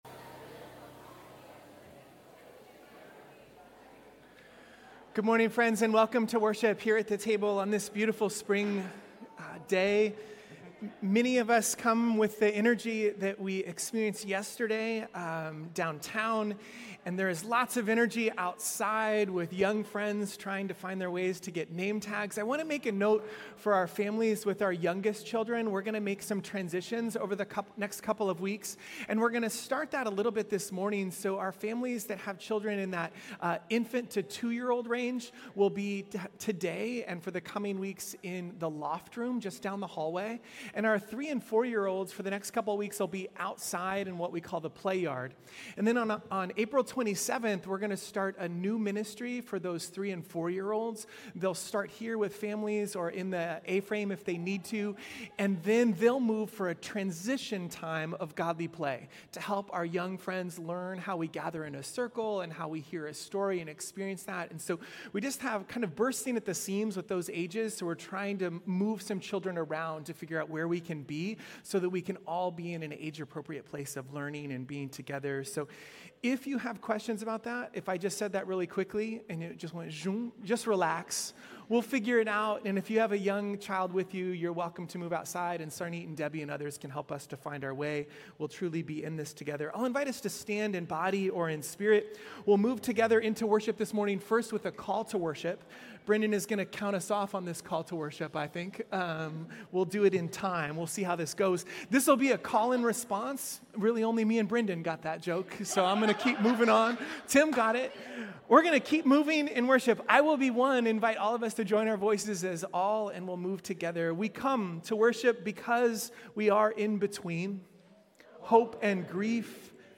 Our Lenten worship series is inspired by A Sanctified Art and called Everything [In] Between.